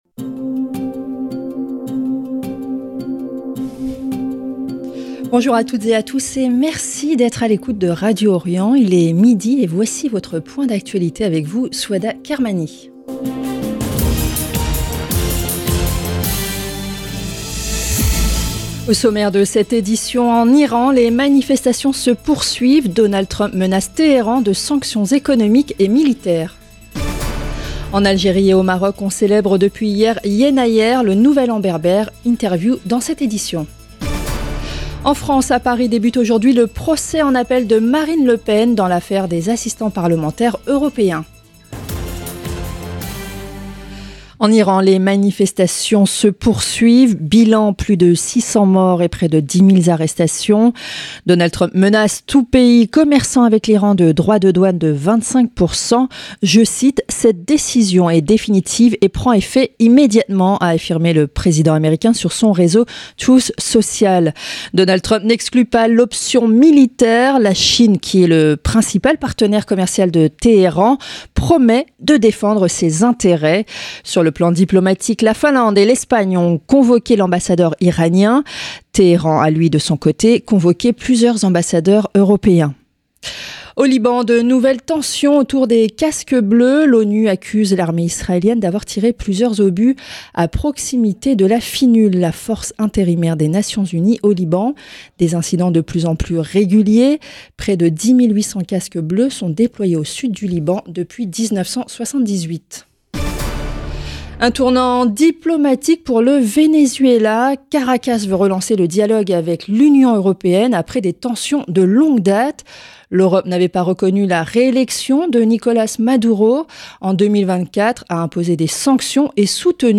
JOURNAL DE MIDI
Donald Trump menace Téhéran de sanctions économiques et militaires. En Algérie et au Maroc on célèbre depuis hier, Yennayer, le nouvel an berbère. Interview dans cette édition.